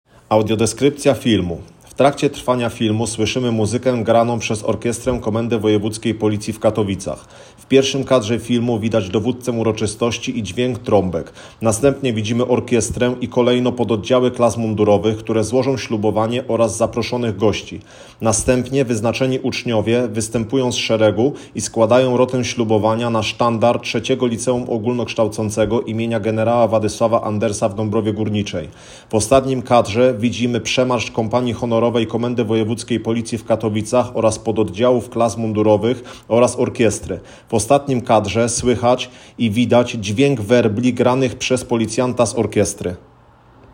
Nagranie audio Audiodeskrypcja_Slubowanie_III_LO.m4a